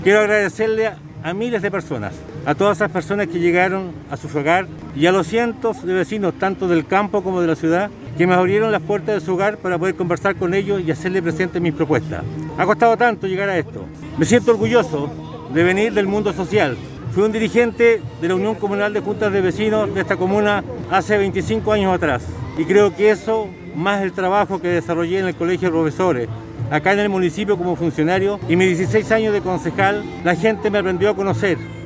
Tras conocer el resultado del 40 por ciento de las mesas escrutadas, Carrillo llegó hasta las inmediaciones de la Plaza de Armas para entregar sus primeras declaraciones como alcalde electo.